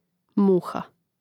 mùha muha